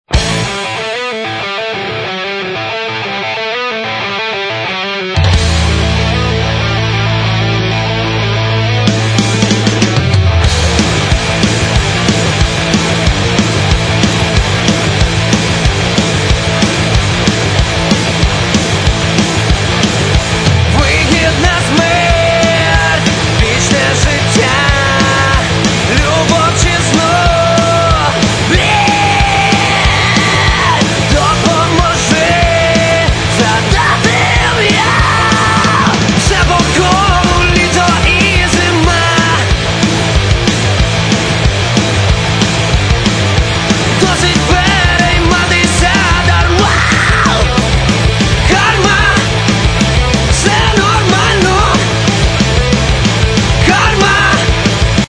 Рок (320)